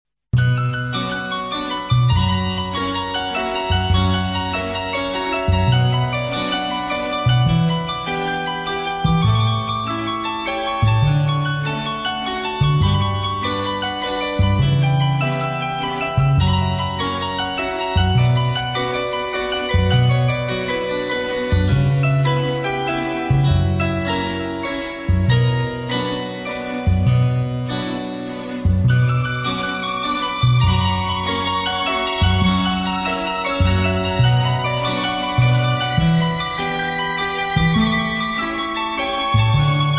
инструментальные , оркестр , без слов